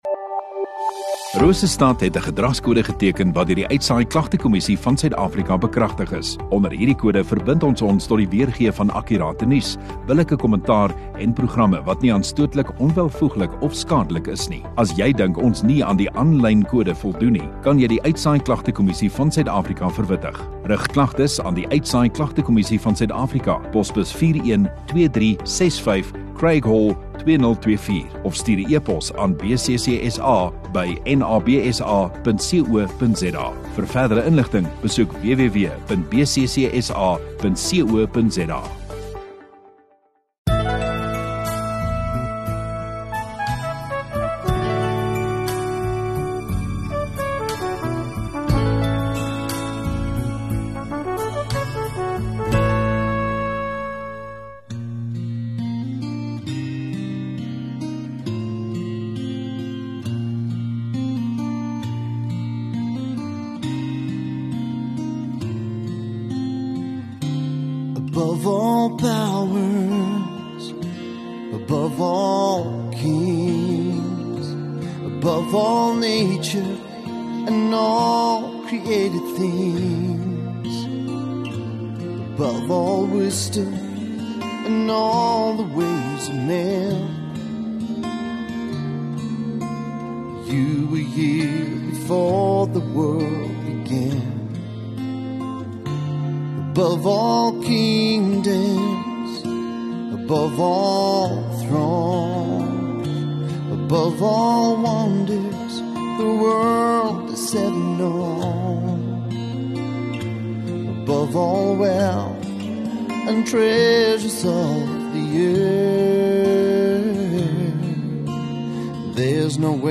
28 Sep Saterdag Oggenddiens